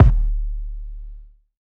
sta_revsub_kick.wav